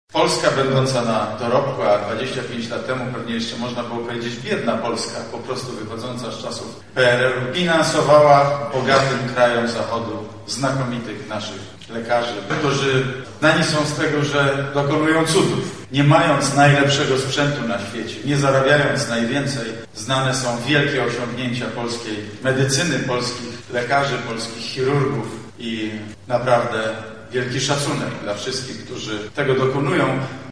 Zabierając głos podczas uroczystości premier Mateusz Morawiecki podkreślił, że w ciągu ostatnich 25-lat z Polski wyjechało prawie 30 tysięcy lekarzy.